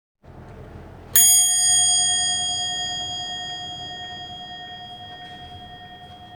Luxusní mechanické kyvadlové hodiny Materiál: Kov V celou hodinu cinknou Natahují se 1x týdně Rozměry bez kyvadla a závaží: š:14 v:25 h:11cm Celková výška: 68 cm ZVUK HODINOVÉHO CINKNUTÍ SI MŮŽETE STÁHNOUT: ZDE
Cink.mp3